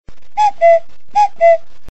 جلوه های صوتی
دانلود صدای حیوانات 22 از ساعد نیوز با لینک مستقیم و کیفیت بالا
برچسب: دانلود آهنگ های افکت صوتی انسان و موجودات زنده دانلود آلبوم مجموعه صدای حیوانات مختلف با سبکی خنده دار از افکت صوتی انسان و موجودات زنده